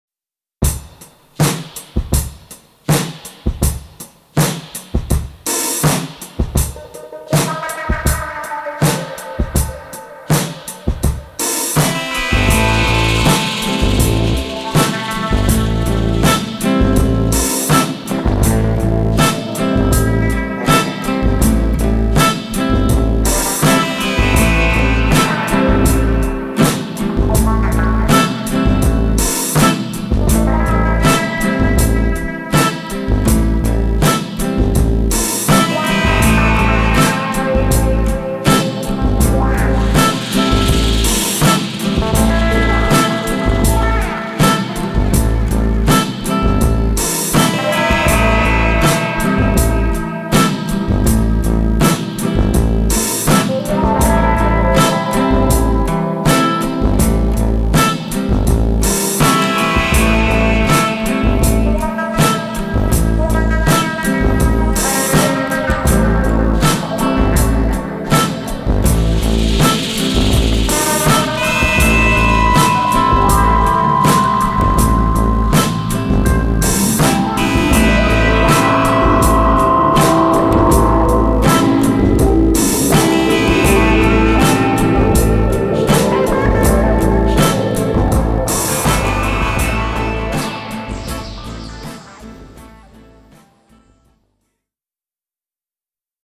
Genre: Funk, Jazz Funk